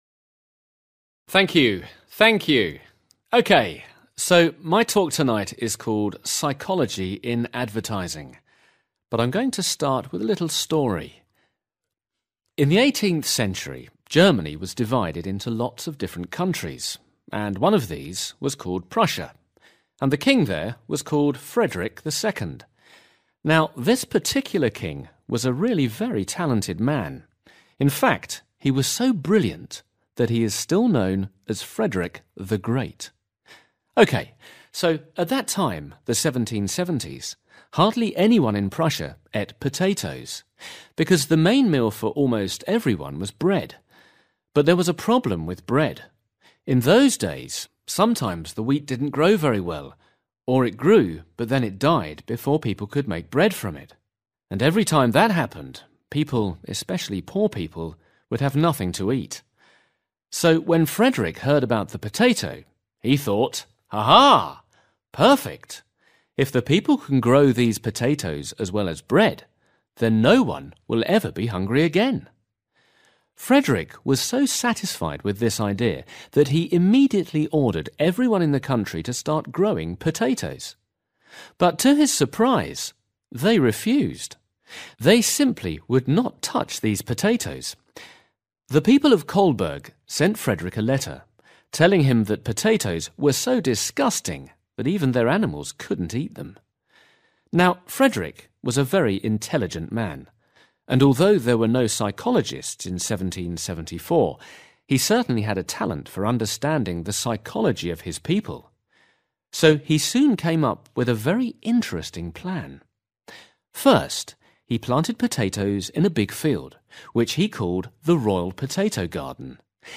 A   Listen to an expert in advertising tell a story during a talk to university students and choose the correct answers.